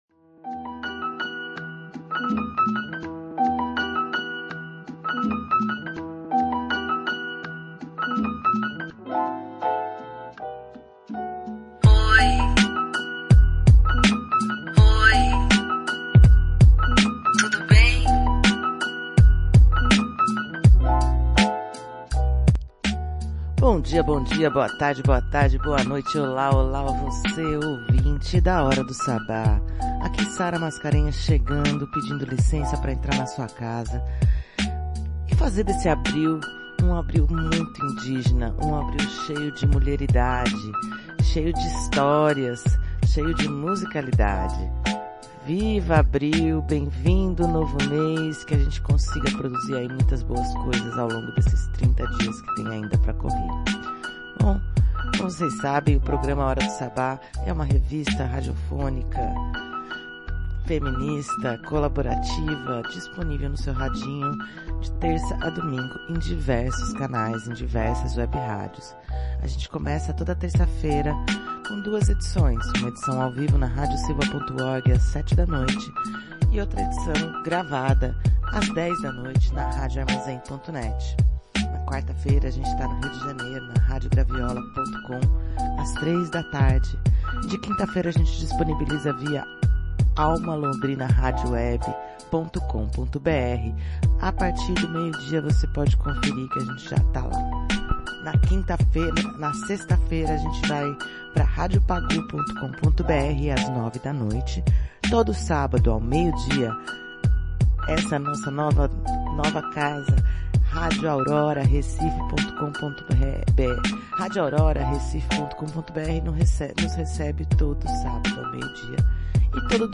O episódio 4 da Temporada 9 da Hora do Sabbat traz uma seleta de sons de mulheres indígenas da música brasileira, a história do céu estrelado no quadro “Fio da História”, um texto de Ivone Mascarenhas e muito mais!